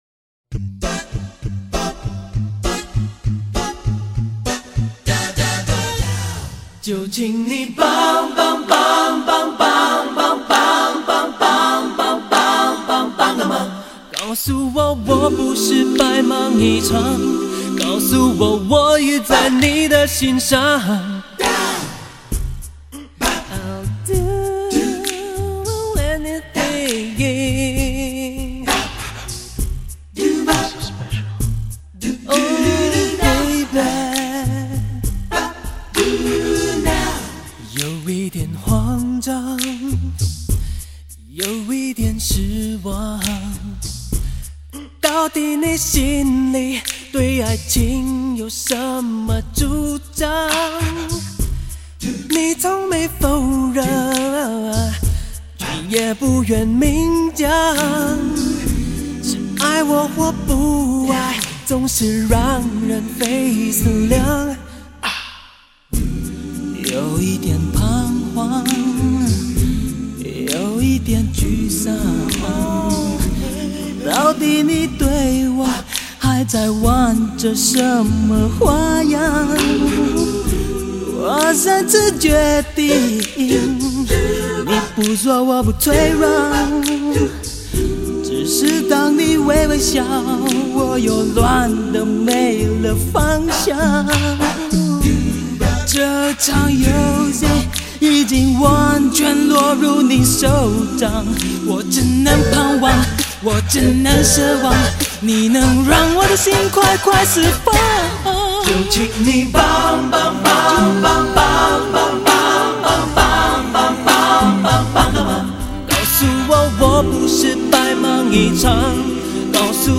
是唯一由亲兄妹组成的合唱团。完美的和声，不凡的创作才华，从十年前他们演唱的歌曲，能感受到当今流行乐坛所有的流行元素。